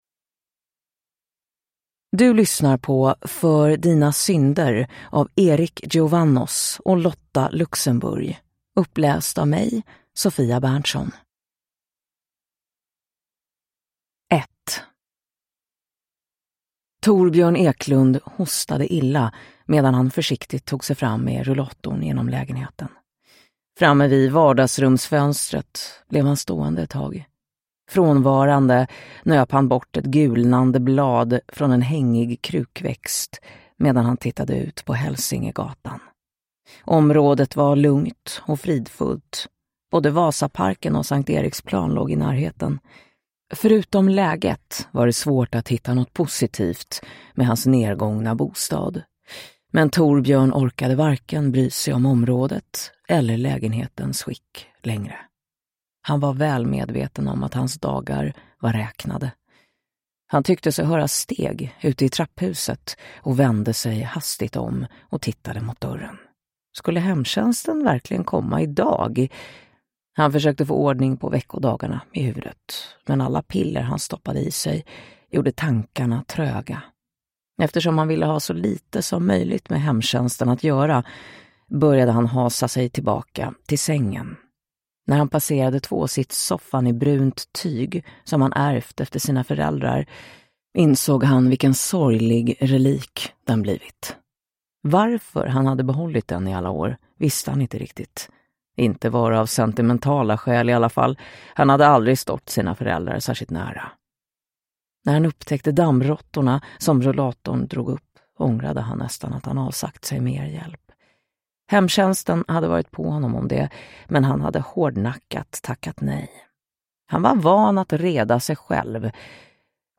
För dina synder – Ljudbok
Deckare & spänning Njut av en bra bok